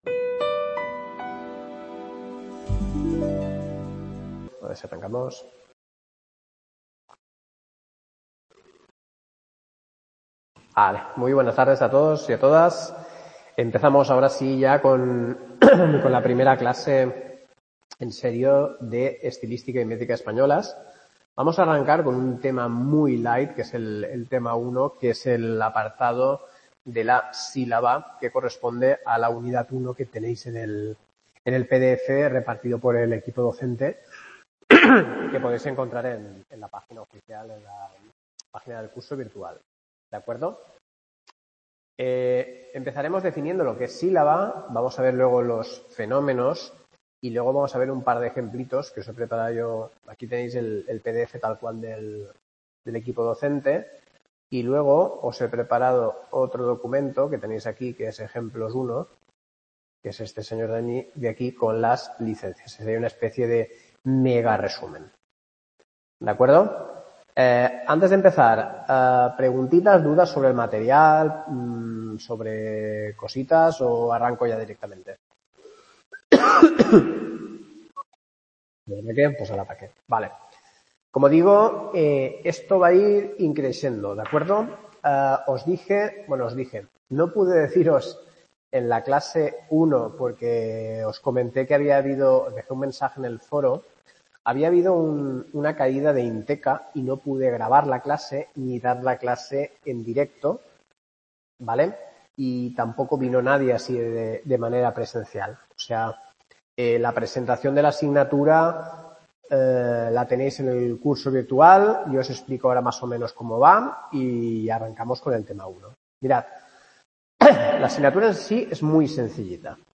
CLASE 2 LA SÍLABA | Repositorio Digital